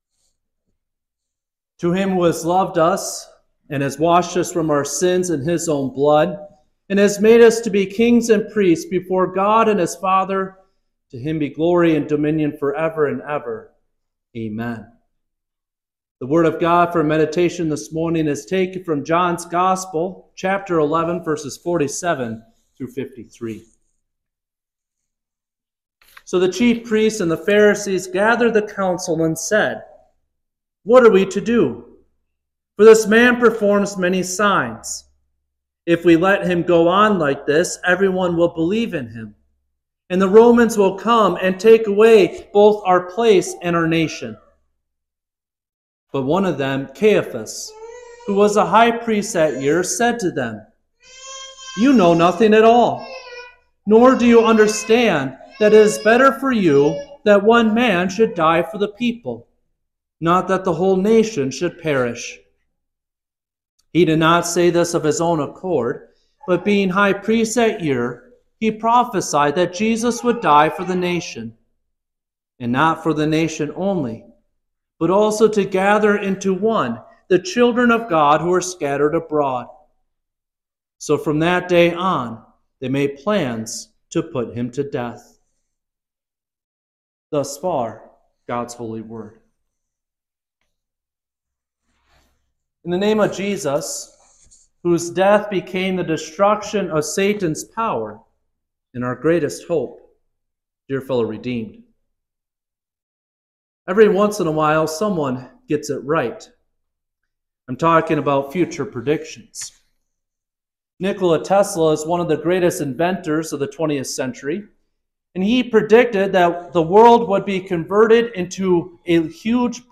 Quinquagesima-Sunday.mp3